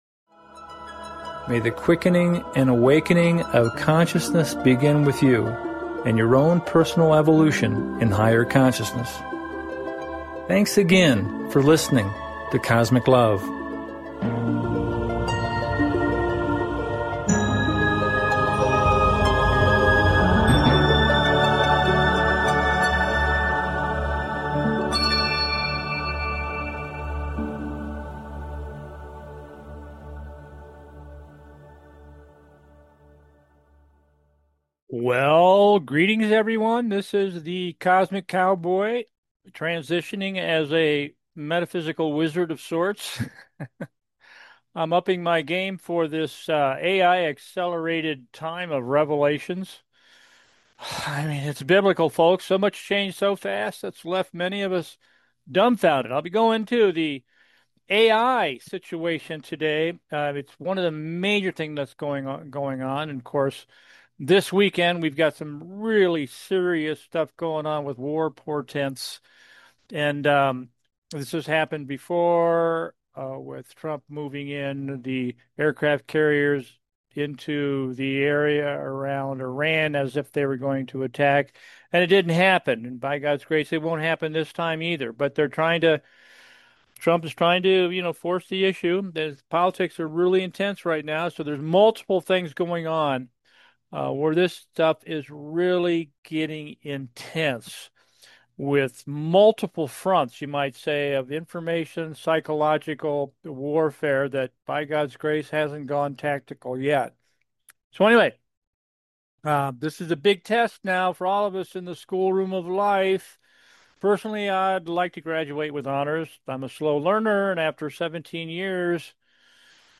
Talk Show Episode, Audio Podcast, Cosmic LOVE and AI Disclosure from 9-11 & Covid to Epstein & Aliens on , show guests , about Cosmic LOVE
Narrating from Three Recent Newsletters: